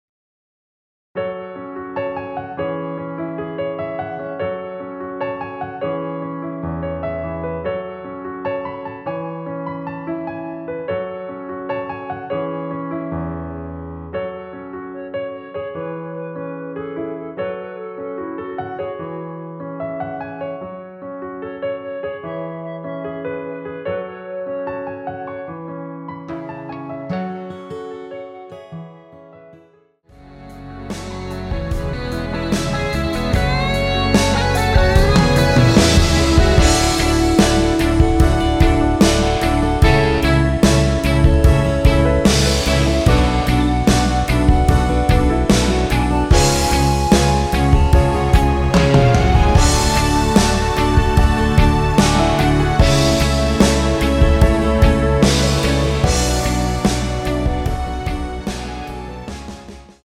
원키에서(-5)내린 멜로디 포함된 MR입니다.
F#m
앞부분30초, 뒷부분30초씩 편집해서 올려 드리고 있습니다.
중간에 음이 끈어지고 다시 나오는 이유는